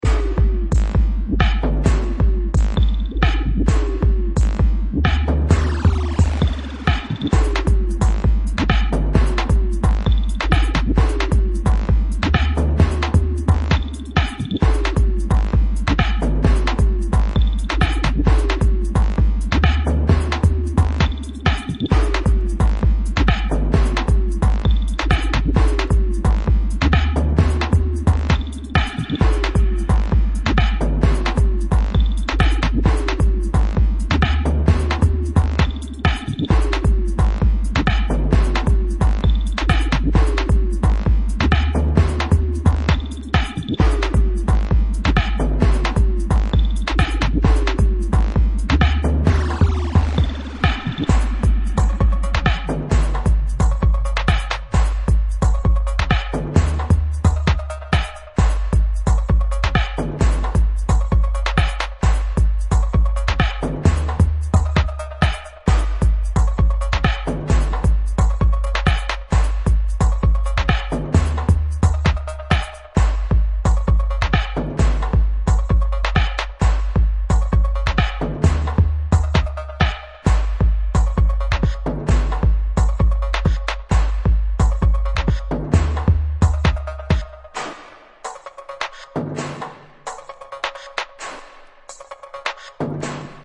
bass music
Electro Techno Bass